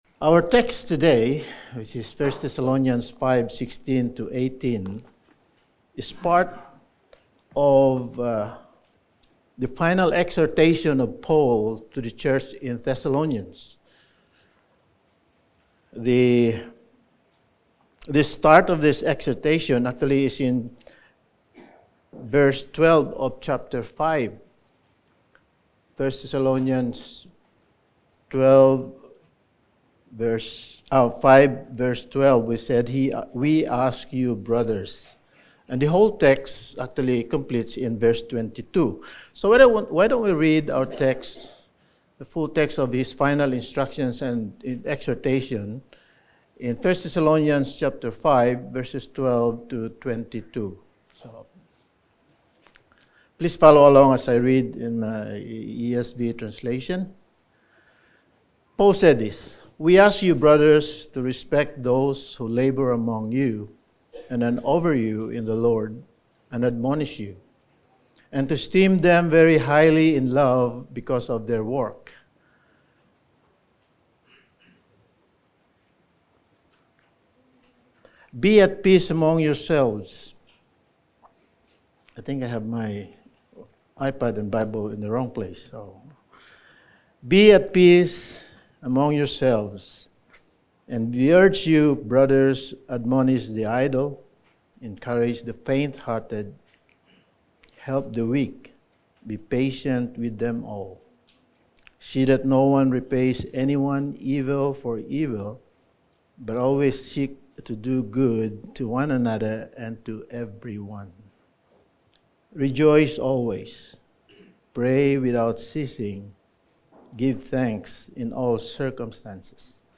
Sermon
1 Thessalonians Series Passage: 1 Thessalonians 5:16-18 Service Type: Sunday Morning Sermon 14 « A Stock-Cube Psalm